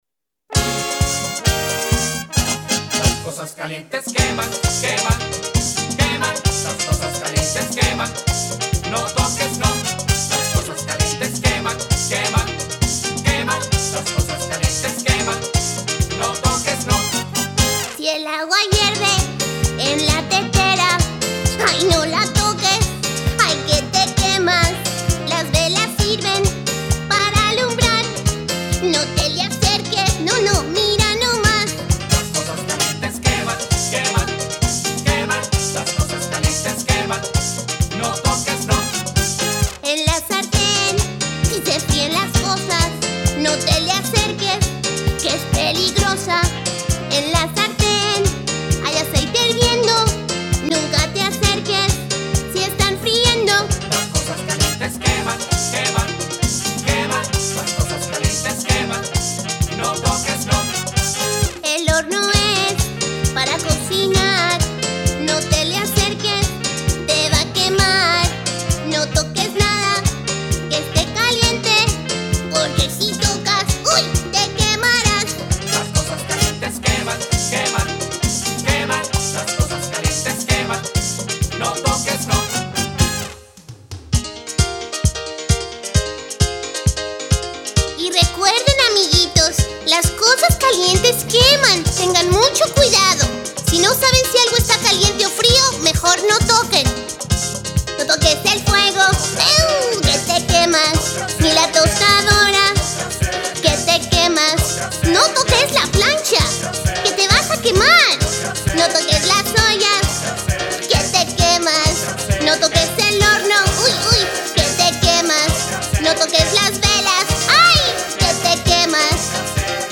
Songs and stories